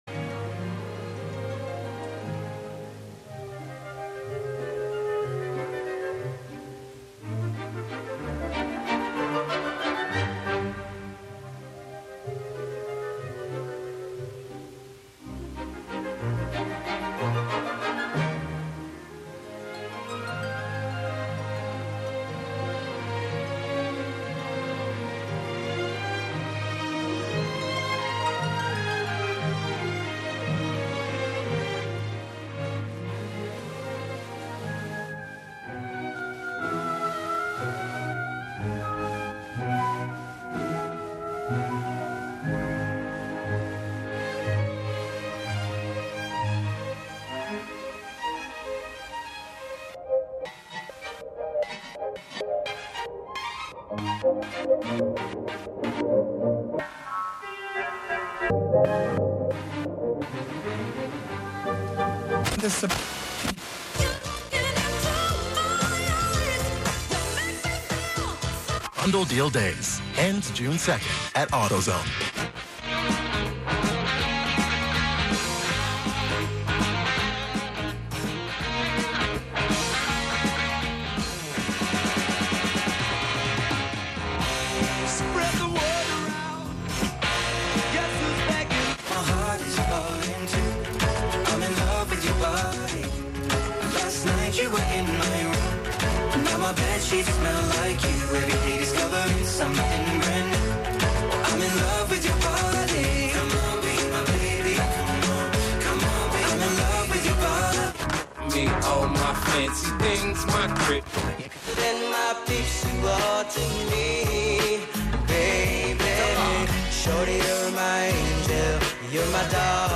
11am Live from Brooklyn, New York
play those S's, T's and K's like a drum machine